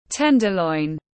Thịt thăn tiếng anh gọi là tenderloin, phiên âm tiếng anh đọc là /ˈten.də.lɔɪn/
Tenderloin /ˈten.də.lɔɪn/
Tenderloin.mp3